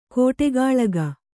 ♪ kōṭegāḷaga